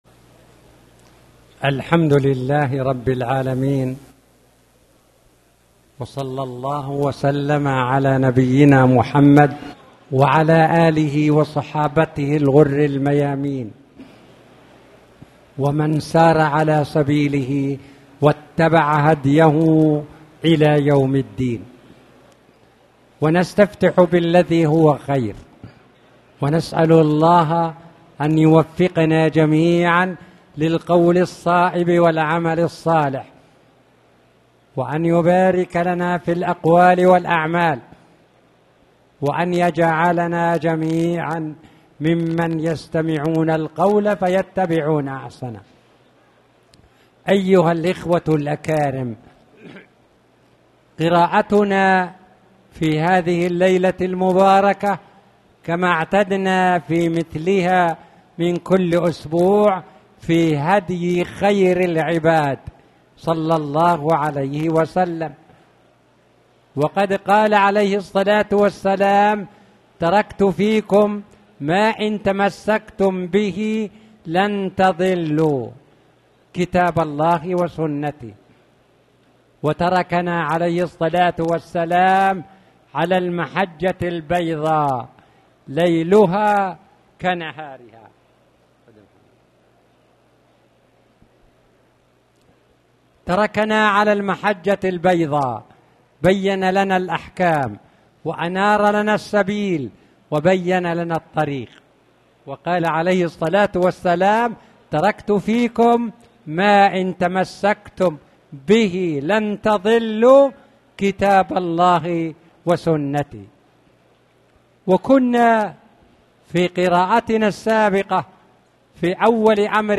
تاريخ النشر ٦ صفر ١٤٣٩ هـ المكان: المسجد الحرام الشيخ